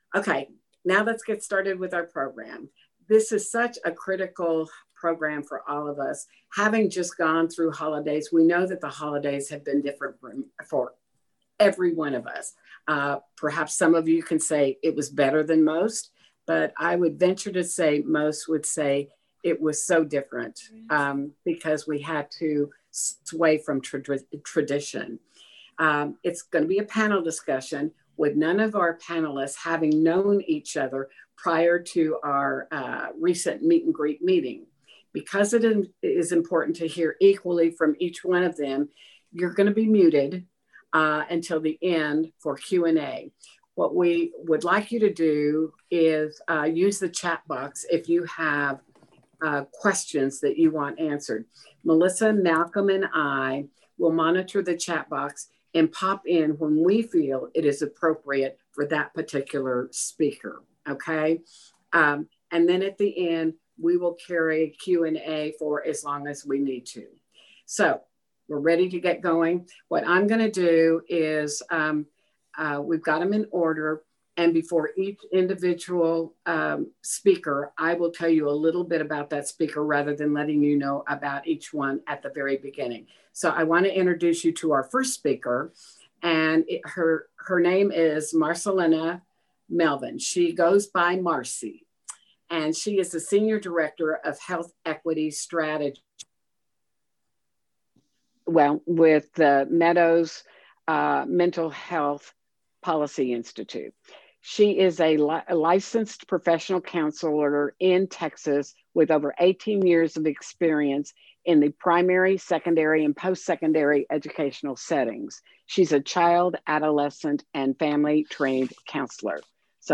Mental Health Online Panel